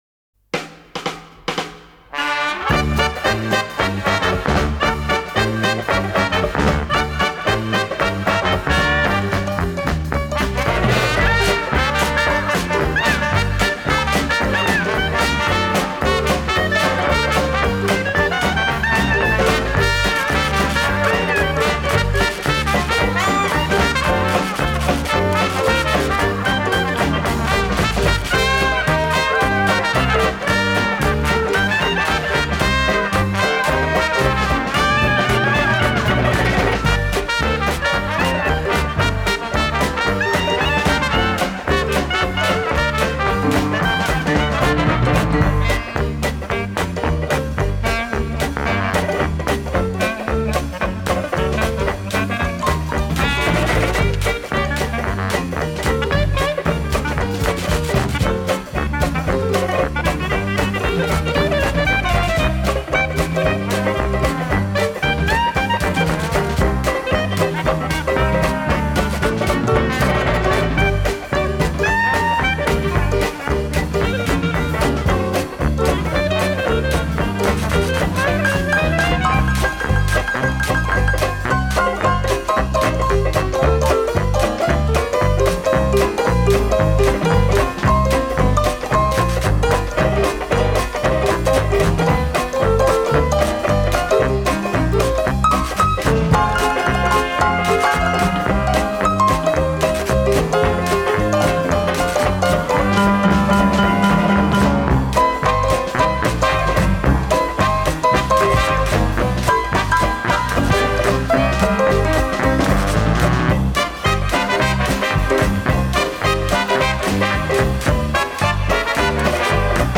ДИКСИ 4